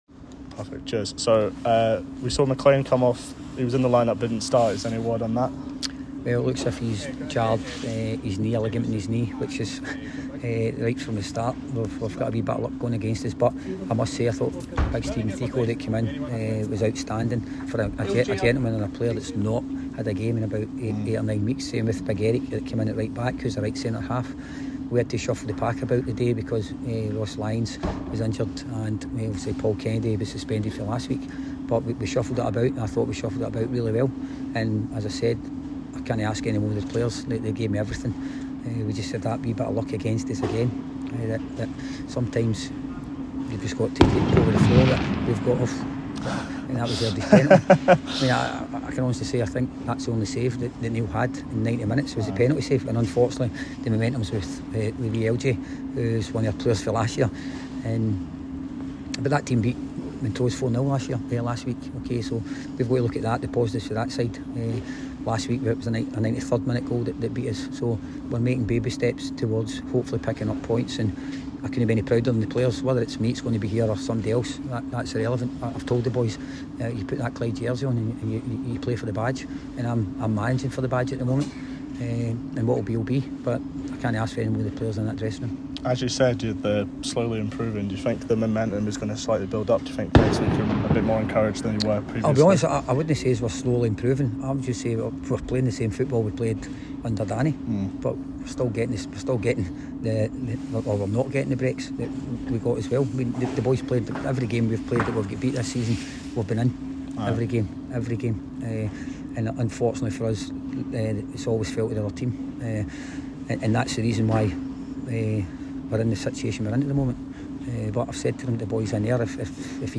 post-match comments